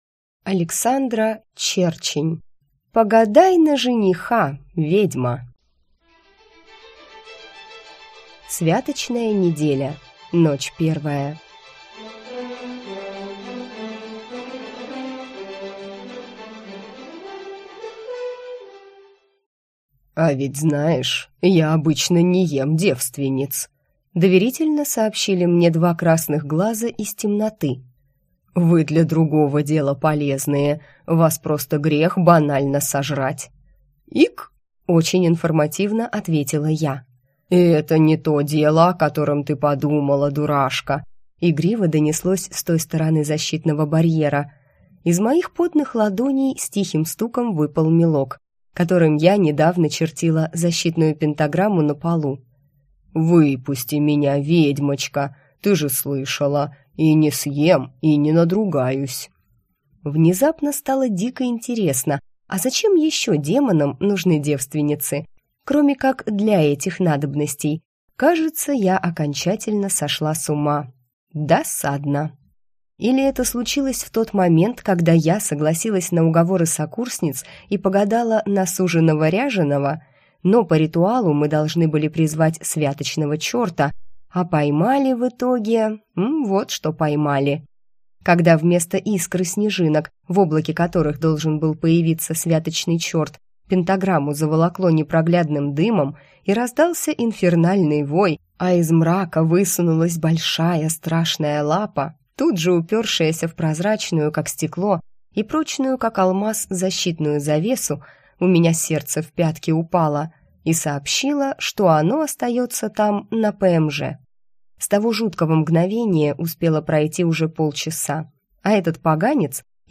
Аудиокнига Погадай на жениха, ведьма!